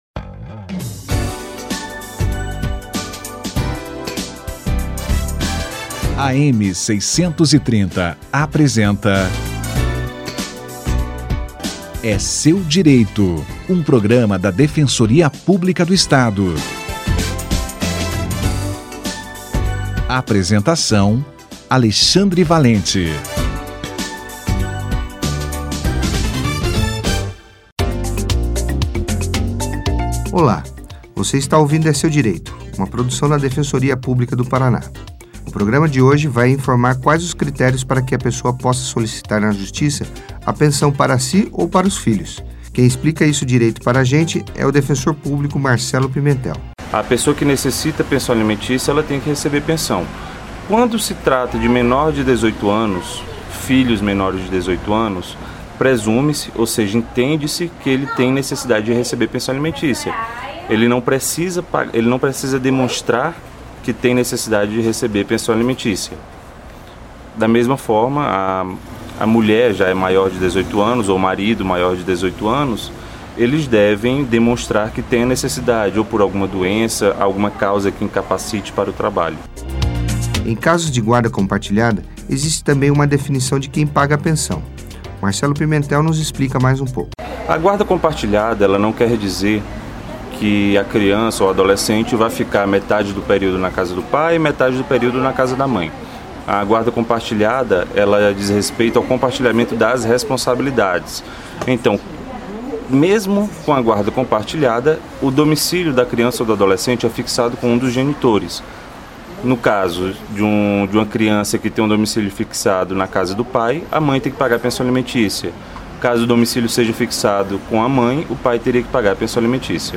17/01/2018 - Solicitação da Pensão Alimentícia - Entrevista Defensor Marcelo Pimentel